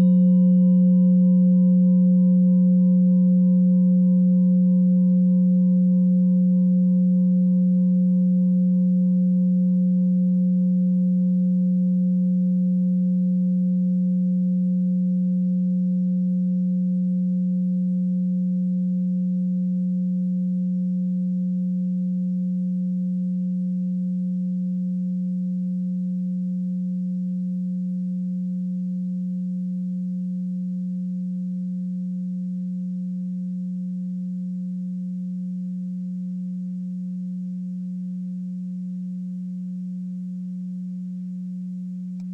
Tibet Klangschale Nr.21, Planetentonschale: Schumannfrequenz
(Ermittelt mit dem Filzklöppel oder Gummikernschlegel)
Der Klang einer Klangschale besteht aus mehreren Teiltönen.
Wie aus dem Tonspektrum hervorgeht, handelt es sich hier um eine Planetentonschale Schumannfrequenz.
Die Klangschale hat bei 124.26 Hz einen Teilton mit einer
Die Klangschale hat bei 363.52 Hz einen Teilton mit einer
klangschale-tibet-21.wav